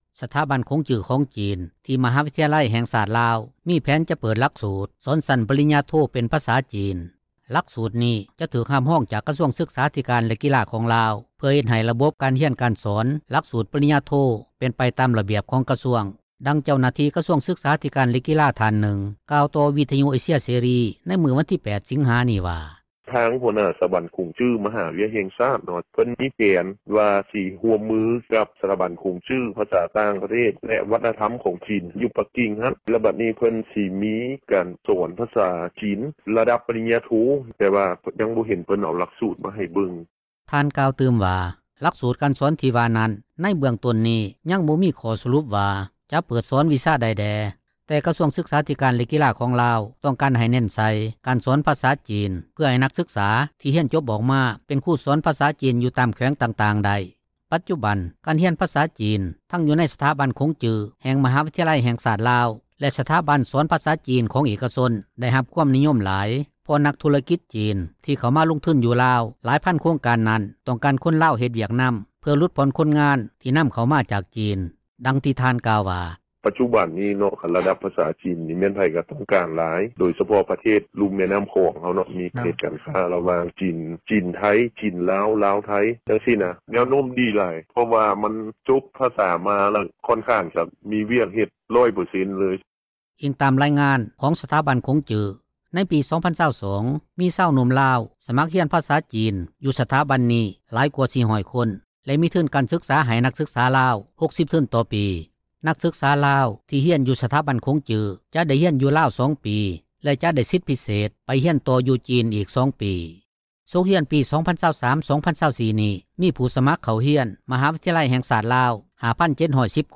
ດັ່ງເຈົ້າໜ້າທີ່ ກະຊວງສຶກສາທິການ ແລະກິລາ ທ່ານນຶ່ງກ່າວຕໍ່ວິທຍຸ ເອເຊັຽ ເສຣີ ໃນມື້ວັນທີ 8 ສິງຫານີ້ວ່າ: